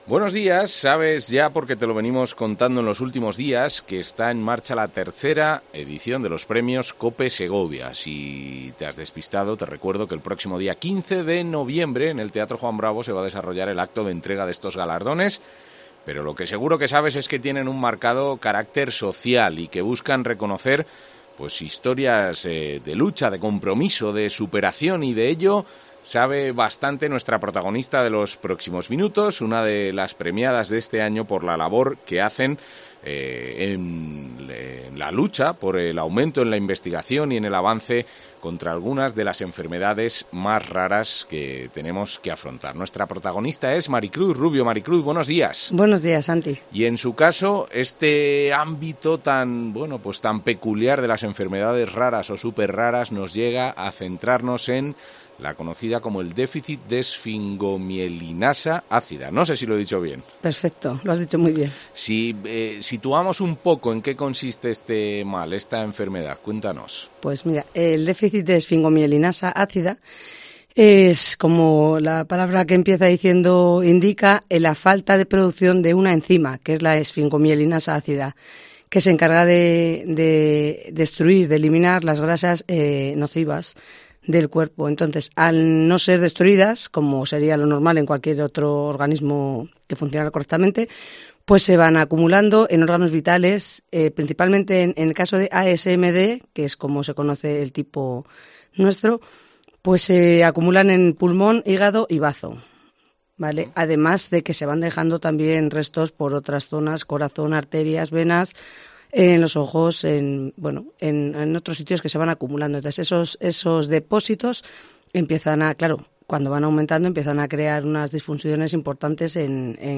en los estudios de COPE Segovia